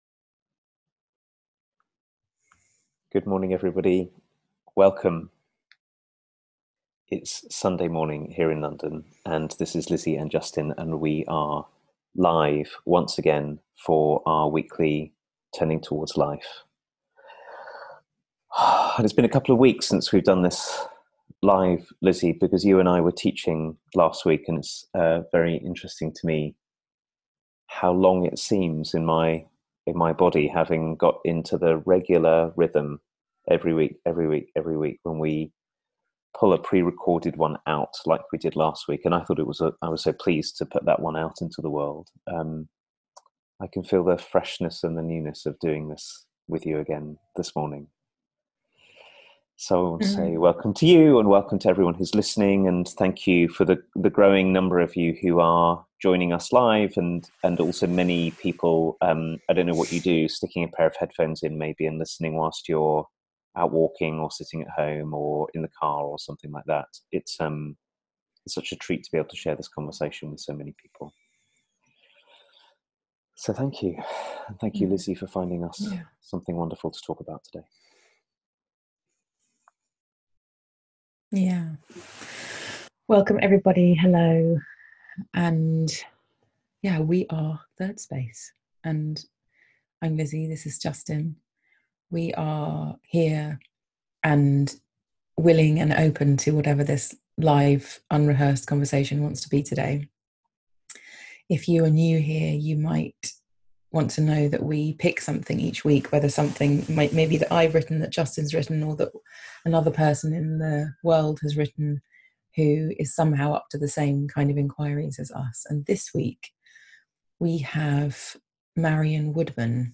A conversation about learning how to step more fully into life